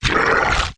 dragon_die2.wav